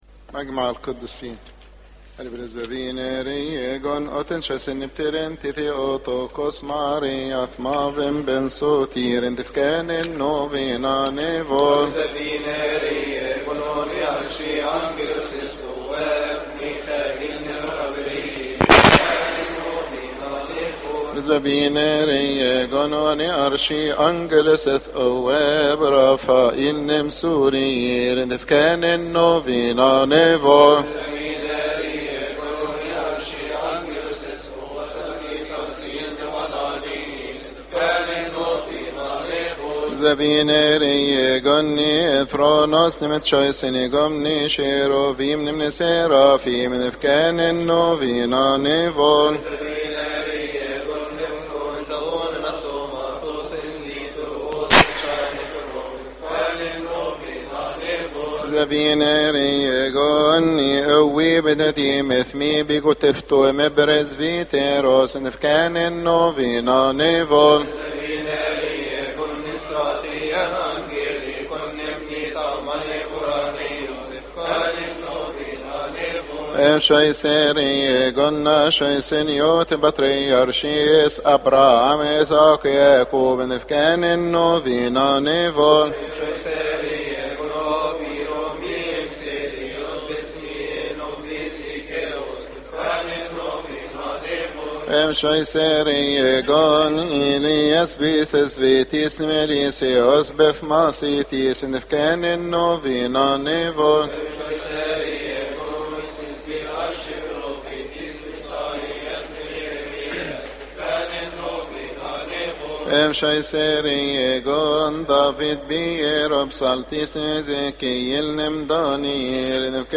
لحن آرى إبريس فيفين إَى إهري إيجون
المصدر : نيافة الأنبا رافائيل يحتوي هذا التسجيل علي: مجمع القديسين (كيهكي) يقال في تسبحة نصف الليل بشهر كيهك لنيافة الأنبا رافائيل، قبطي مجمع القديسين (كيهكي) المصدر: نيافة الأنبا رافائيل اضغط هنا لتحميل اللحن
تسبحة-كيهك-مجمع-القديسين-يصليها-نيافة-انبا-رافائيل-_-Athanasius-Deacons.mp3